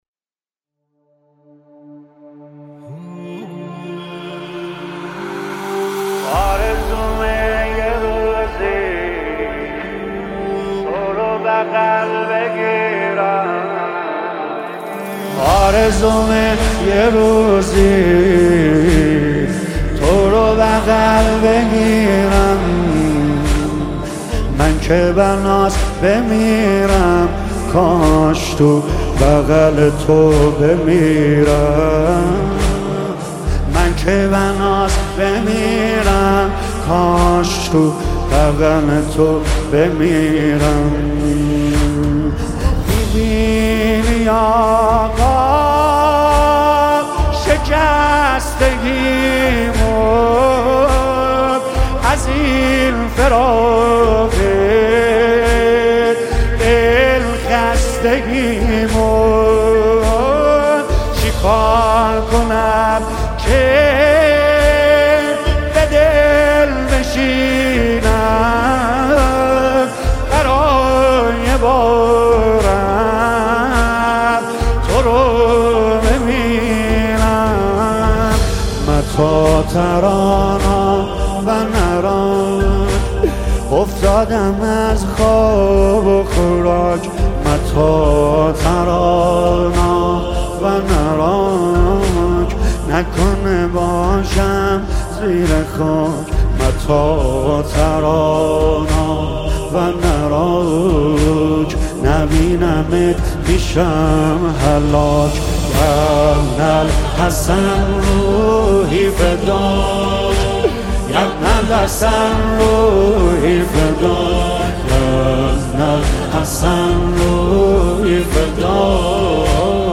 ویژه ماه محرم